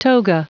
Prononciation du mot toga en anglais (fichier audio)
Prononciation du mot : toga